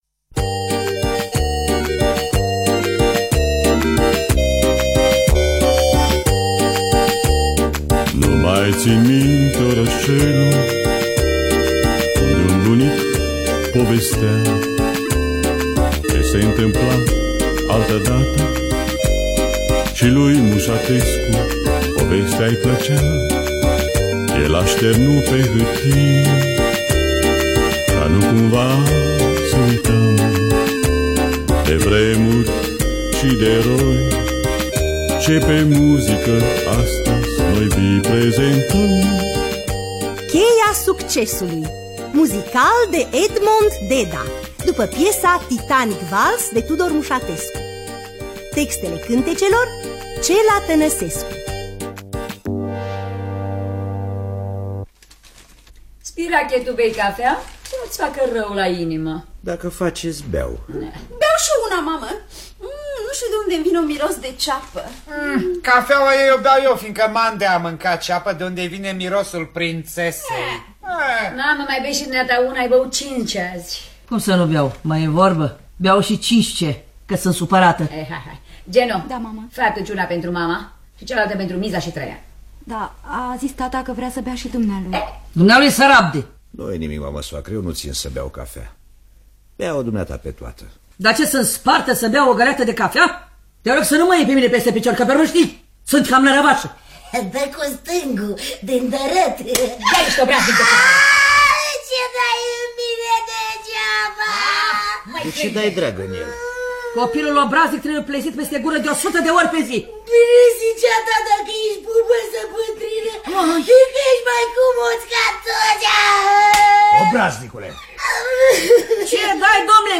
Cheia succesului sau Titanic vals de Tudor Mușatescu – Teatru Radiofonic Online